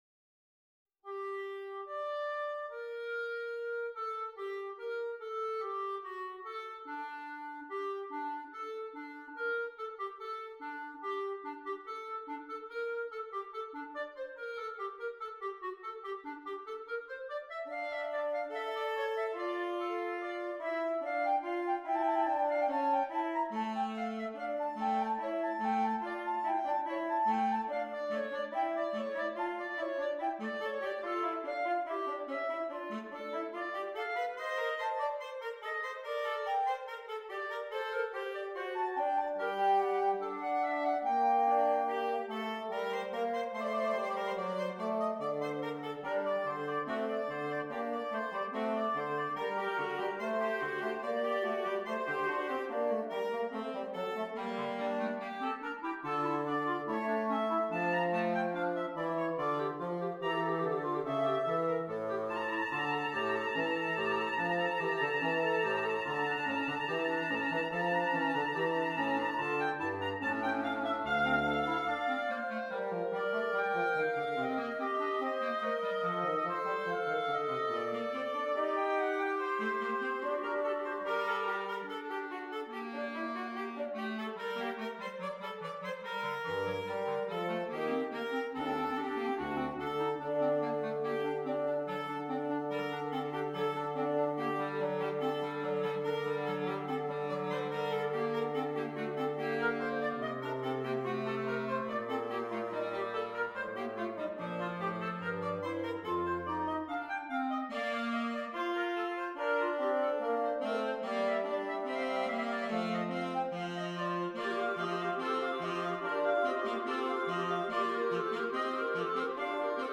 Interchangeable Woodwind Ensemble
PART 1 - Flute, Oboe, Clarinet
PART 5 - Baritone Saxophone, Bass Clarinet, Bassoon